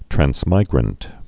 (trăns-mīgrənt, trănz-)